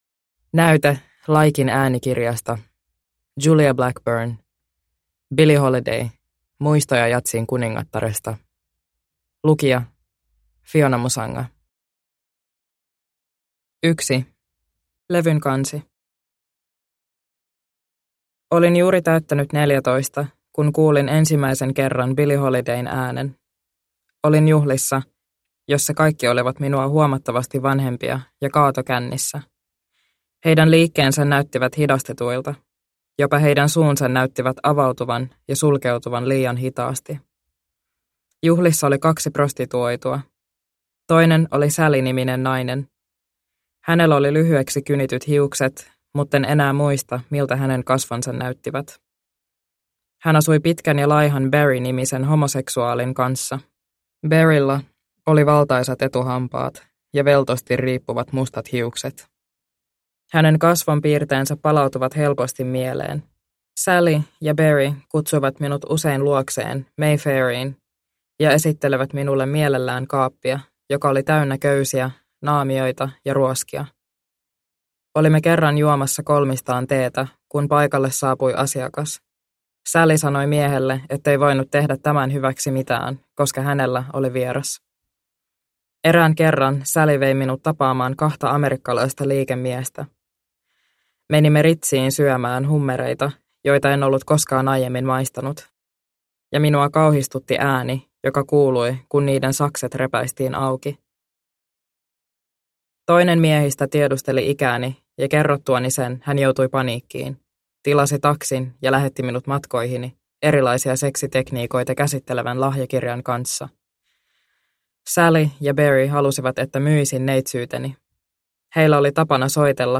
Billie Holiday – Ljudbok – Laddas ner